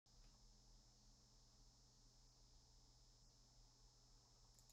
электрический шум
всем привет недавно купил оборудование для записи вокала, akg p220 и focusrite scarlett solo 3gen, опыт с аудиокартами впервые подключил микрофон к аудиокарте установил драйвера, но при мониторинге звука и записи в разных прогах проявляется довольно громкий шум (прикреплю файл с записью) буду...